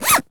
foley_zip_zipper_long_06.wav